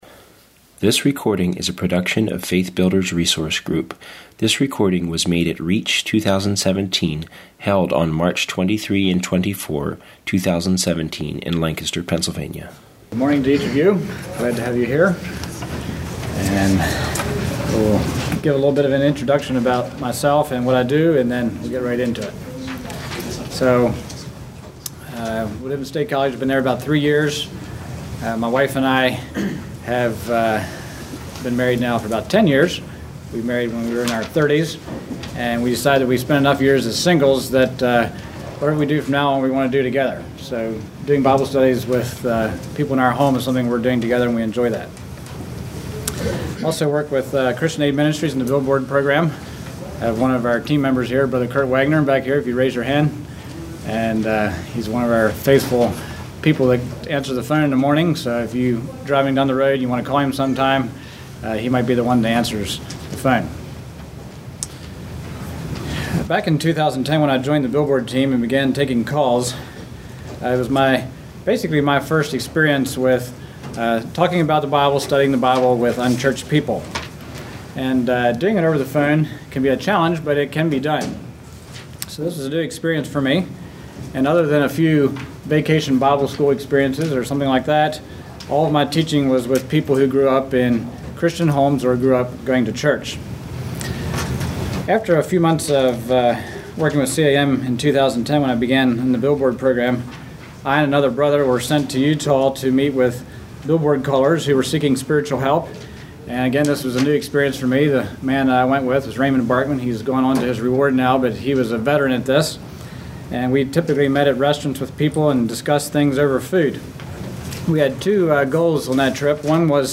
Home » Lectures » Conducting Bible Studies with the Unchurched